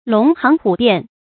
龍行虎變 注音： ㄌㄨㄙˊ ㄒㄧㄥˊ ㄏㄨˇ ㄅㄧㄢˋ 讀音讀法： 意思解釋： 《易·干》：「飛龍在天……云從龍。